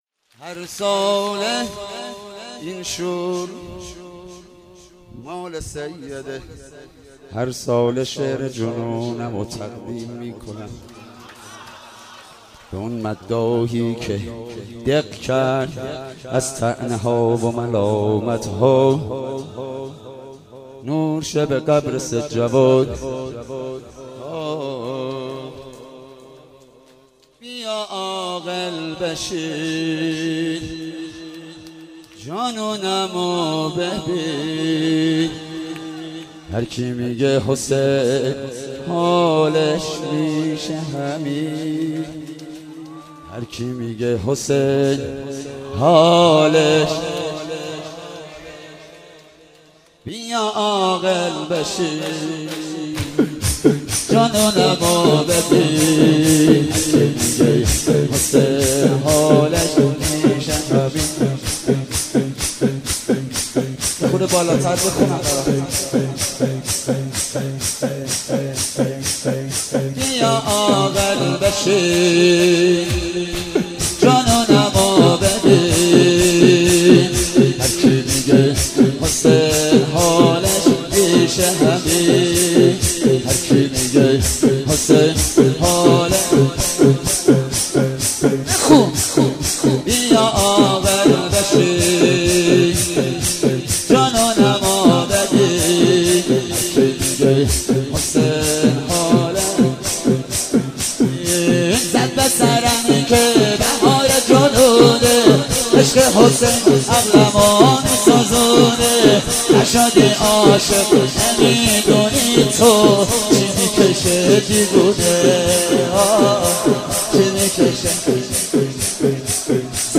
محرم 95
شور